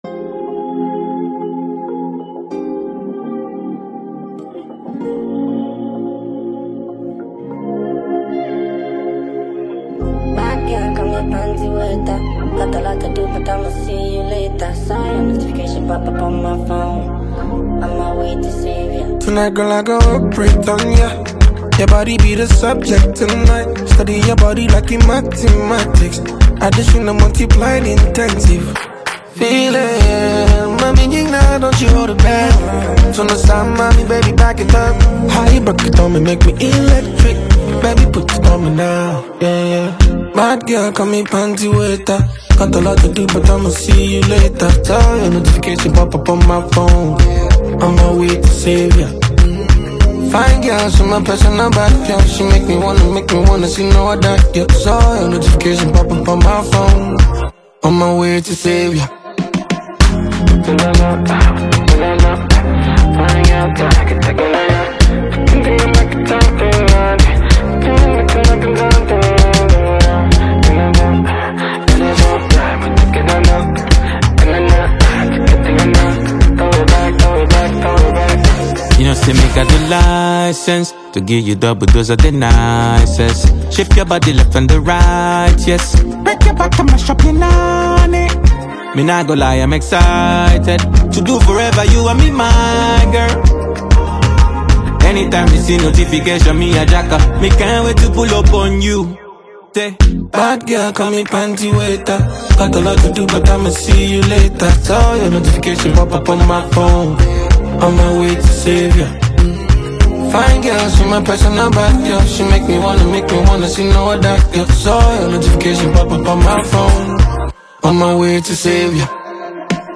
• Genre: Afrobeats / Dancehall-infused Afrobeats